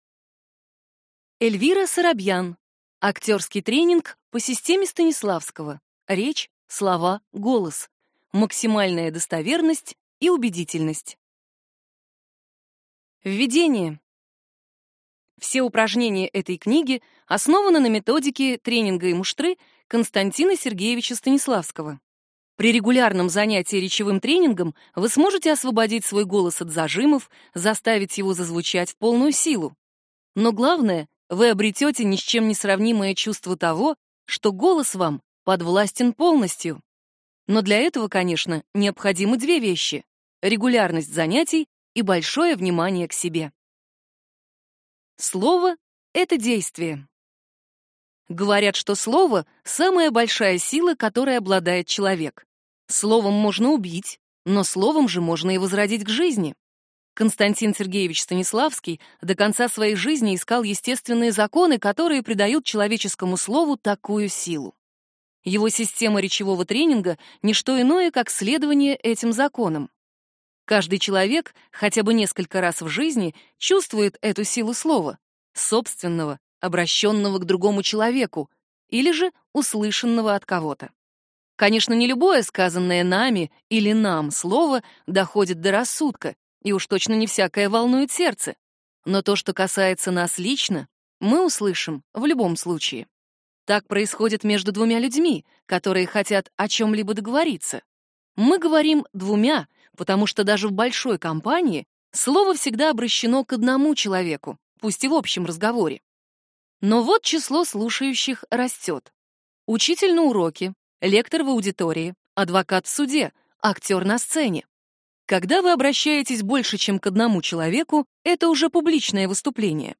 Аудиокнига Актерский тренинг по системе Станиславского. Речь. Слова. Голос. Максимальная достоверность и убедительность | Библиотека аудиокниг